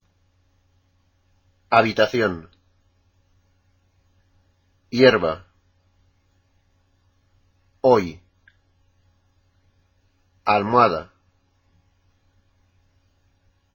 La "h" en español no se pronuncia.